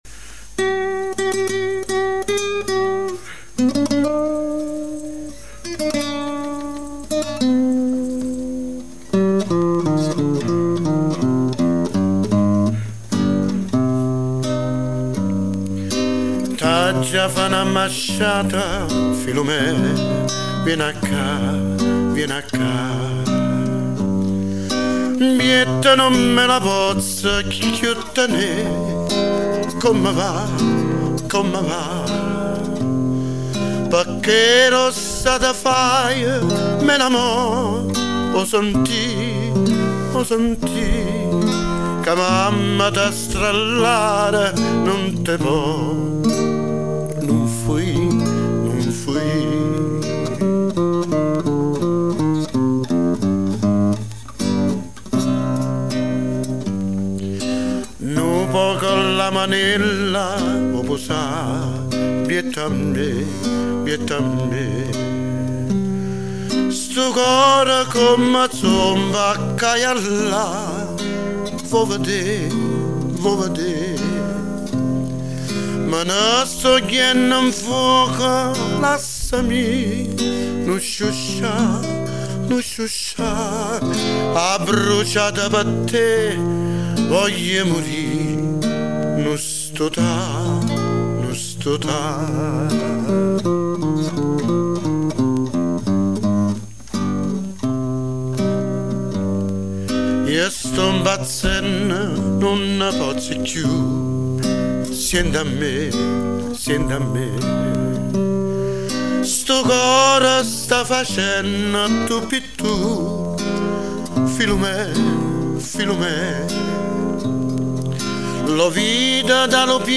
Canzoni classiche vesuviane